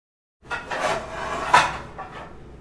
厨房的声音 " 取出煎锅
描述：厨房的声音